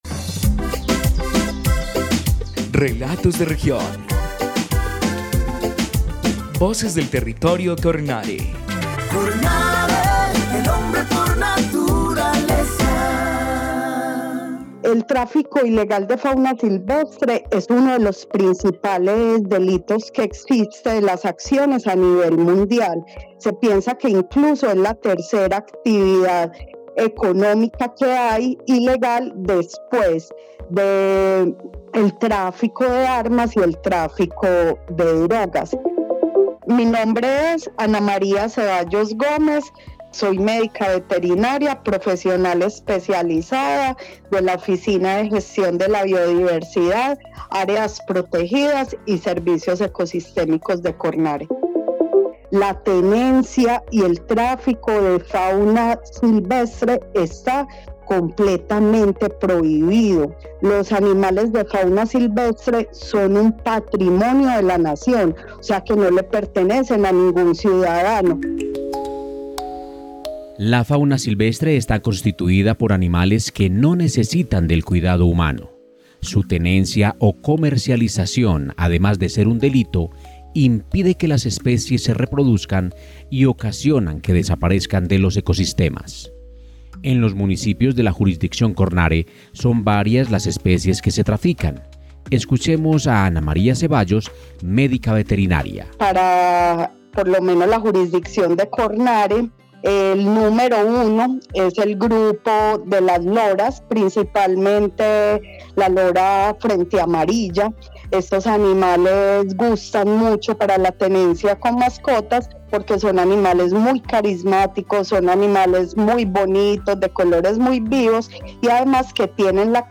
Programa de radio 2024